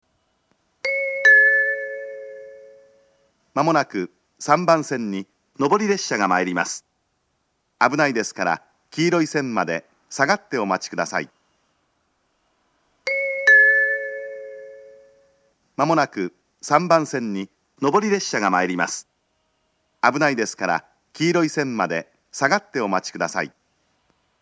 接近放送前のチャイムは上下で何故か異なるものを使用しています。
３番線上り接近放送